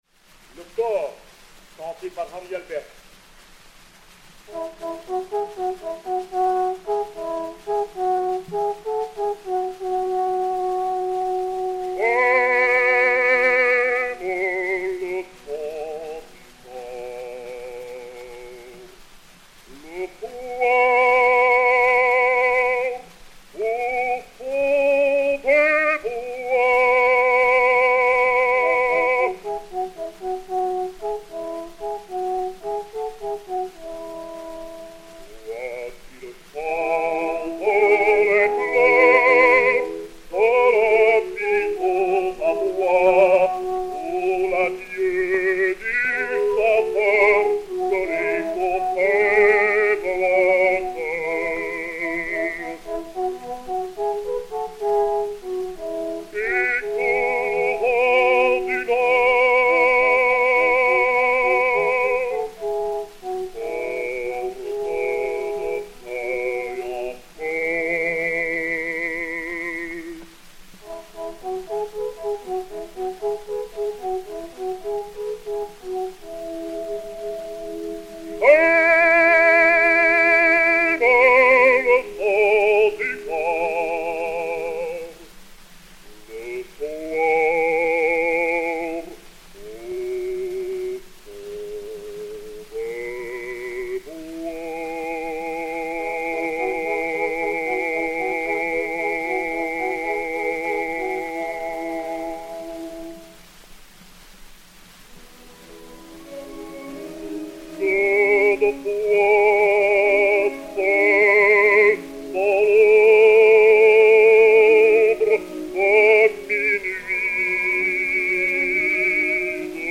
Henri Albers et Orchestre